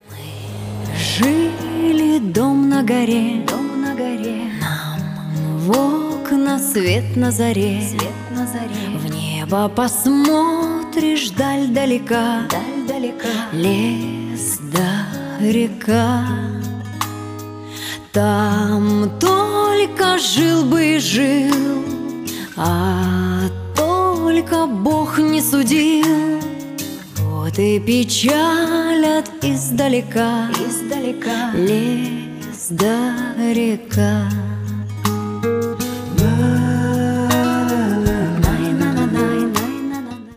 Шансон
спокойные